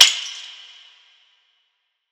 Perc 4 [ hit ].wav